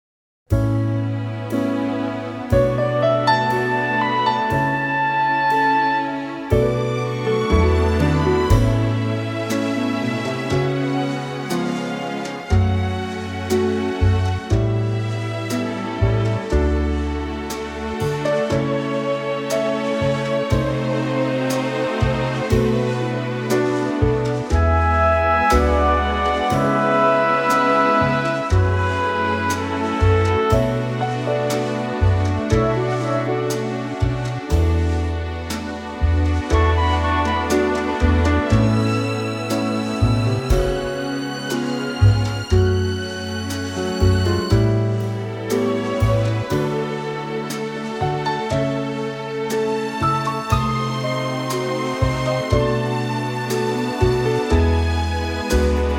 key - A - vocal range - (optional E) A to C#
Gorgeous orchestral arrangement